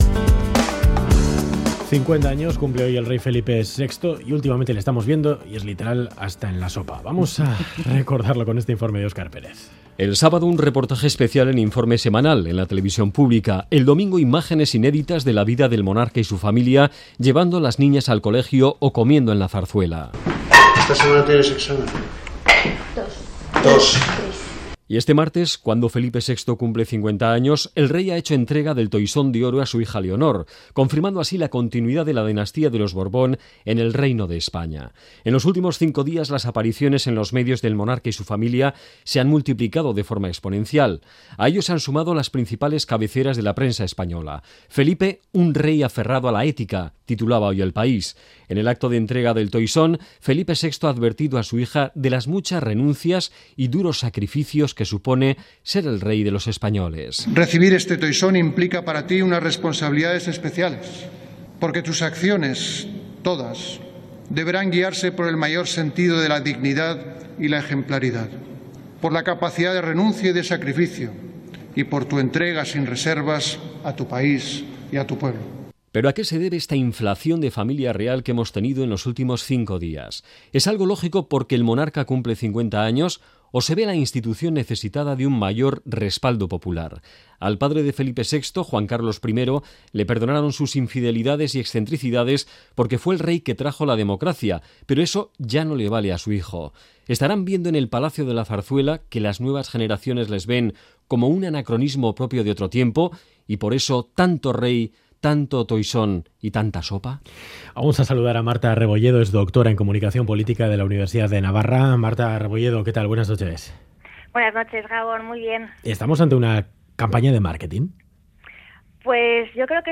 Radio Euskadi GANBARA El giro comunicativo de la Casa Real Última actualización: 30/01/2018 22:53 (UTC+1) Las apariciones en los medios del monarca y su familia se han multiplicado de forma exponencial. Entrevista